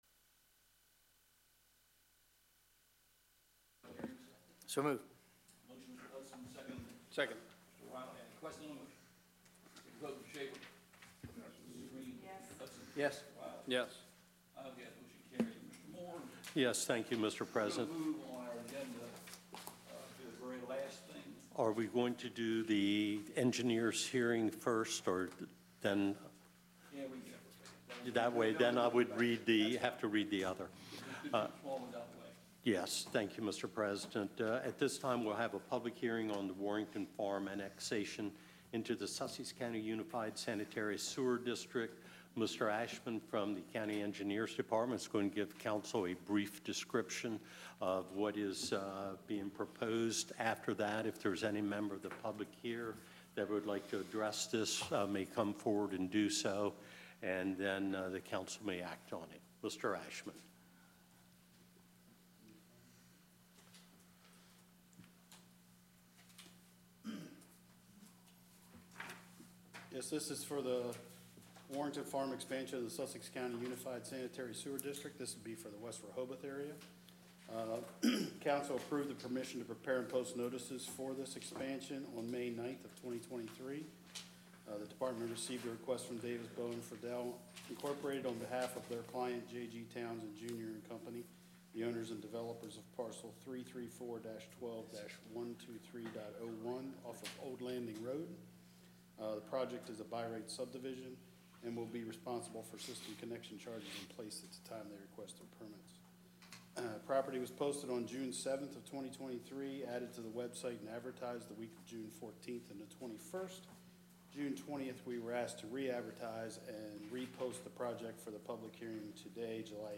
County Council Meeting | Sussex County
Meeting location: Council Chambers, Sussex County Administrative Office Building, 2 The Circle, Georgetown